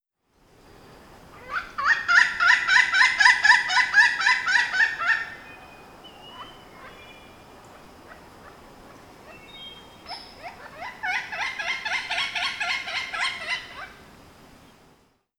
ForrestBird3.wav